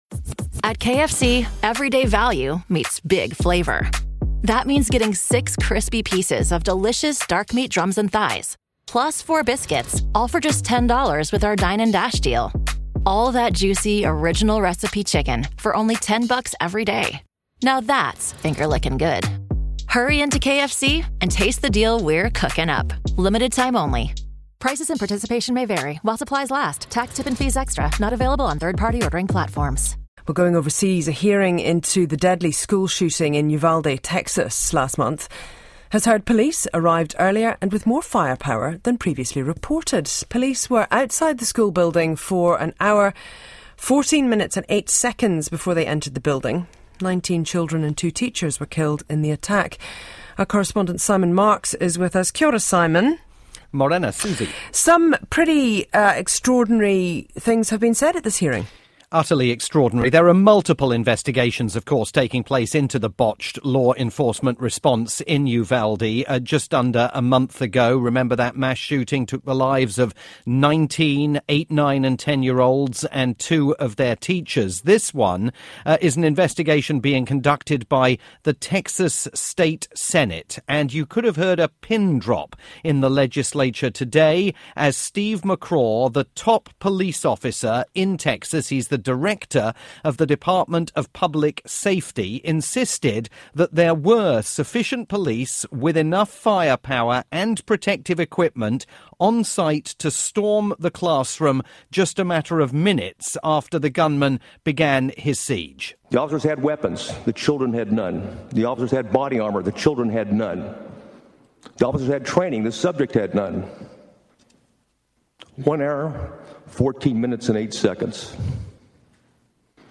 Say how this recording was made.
live report for Radio New Zealand's "Morning Report"